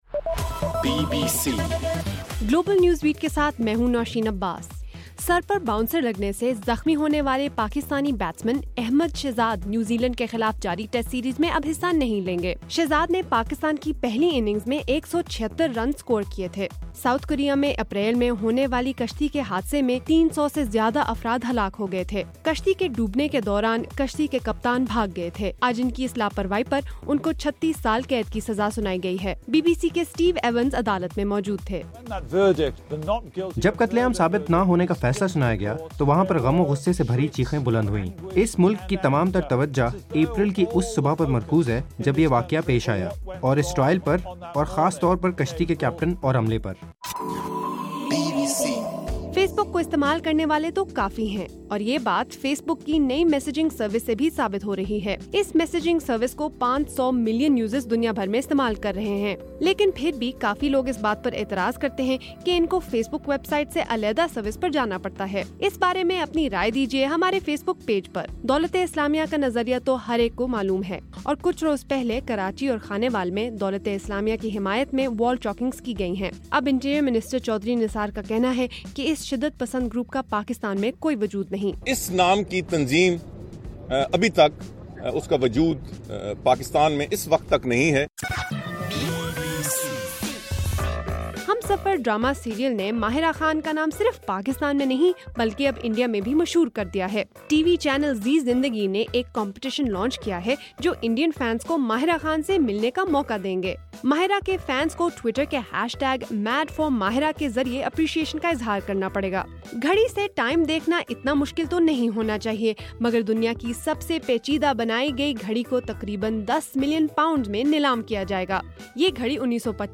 نومبر 11 : رات 8 بجے کا گلوبل نیوز بیٹ بُلیٹن